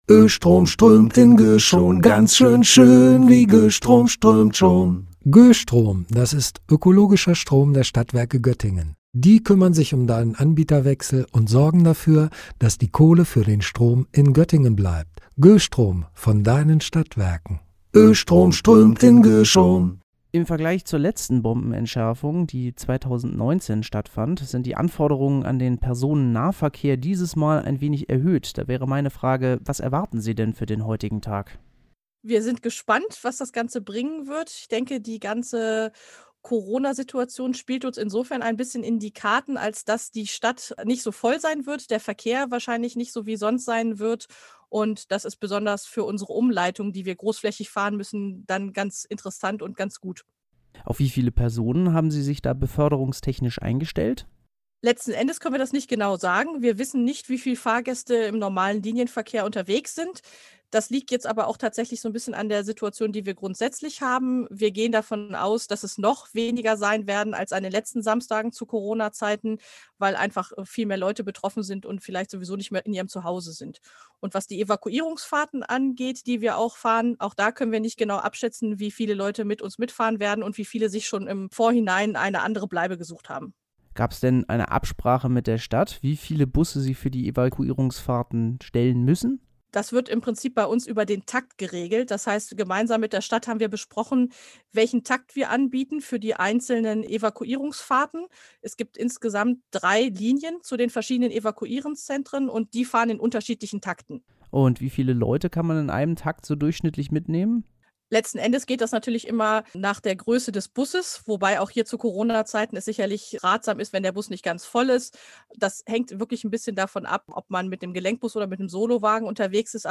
Beiträge > Wie geht Evakuierung? – Ein Gespräch mit den GöVB - StadtRadio Göttingen